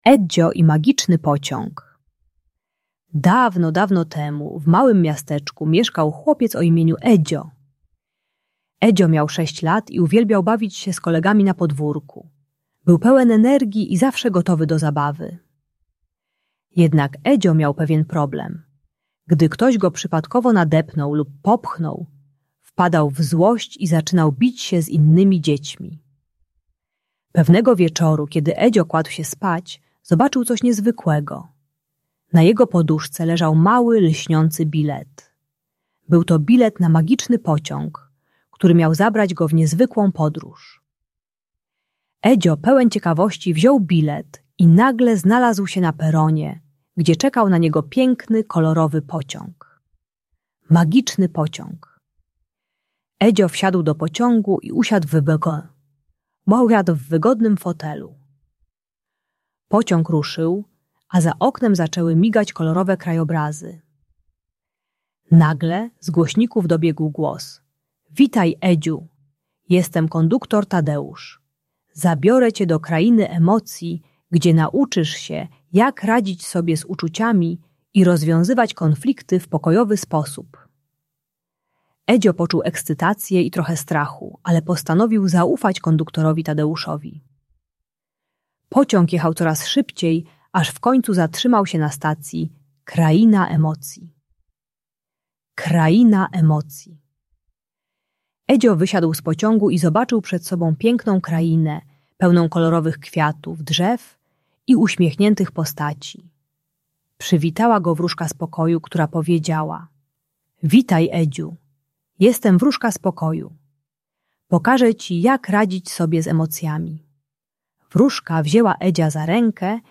Edzio i Magiczny Pociąg - Bunt i wybuchy złości | Audiobajka